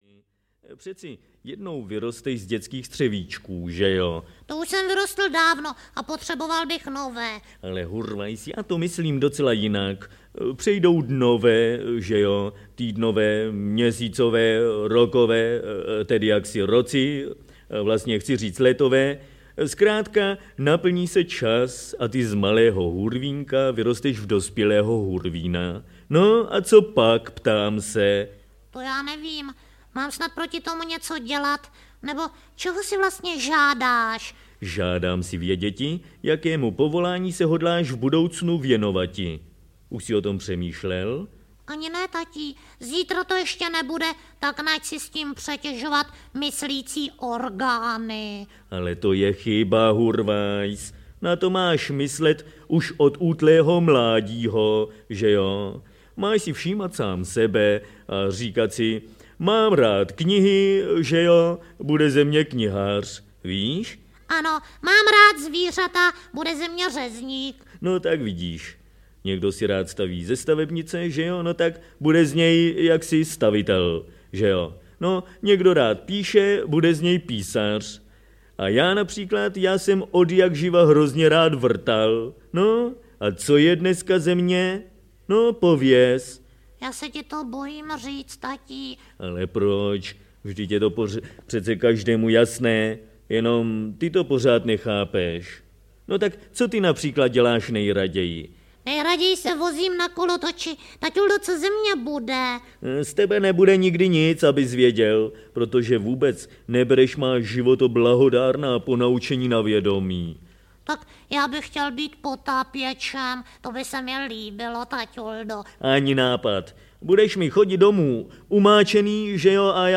Audiokniha Pohádky 7 obsahuje sedmičku známých pohádkových příběhů, čtených předními českými interprety jako jsou Vlastimil Brodský, Dana Medřická nebo Petr Názožný.
Audio kniha